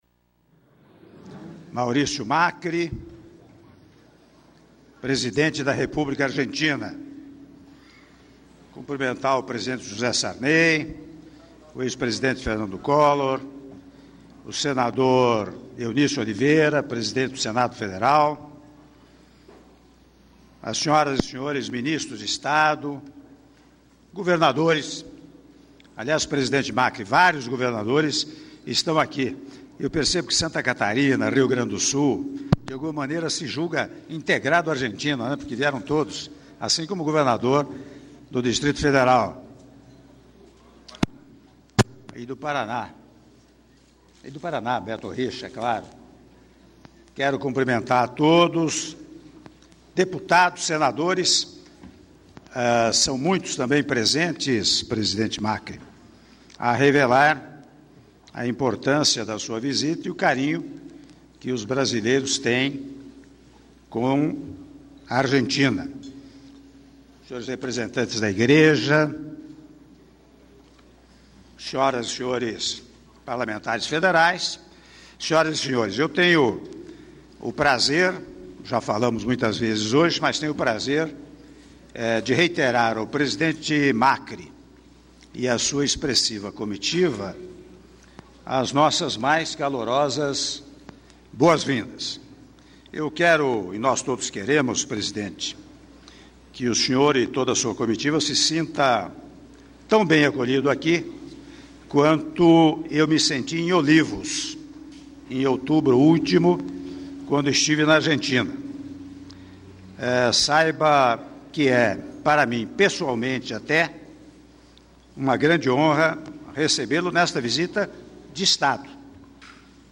Áudio do discurso do presidente da República, Michel Temer, durante o almoço oferecido ao presidente da República Argentina, Mauricio Macri - Brasília/DF - (07min24s)